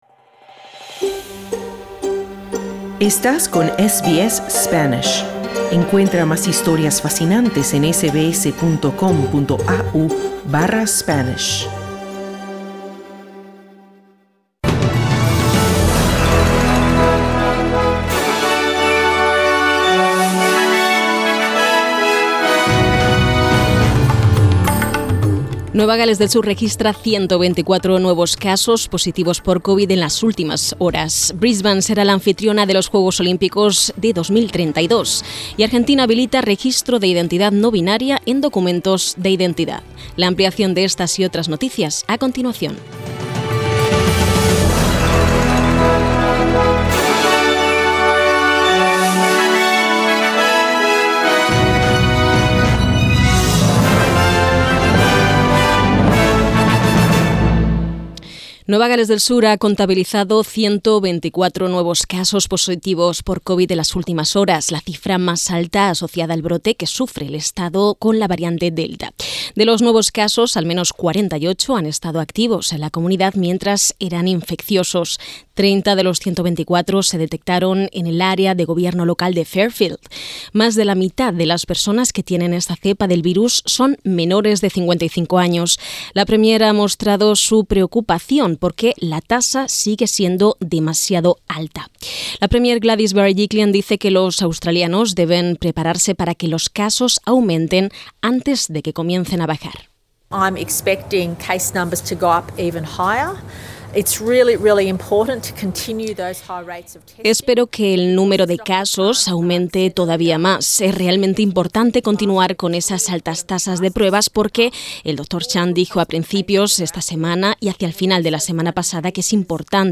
Boletin de noticias 22 julio 2021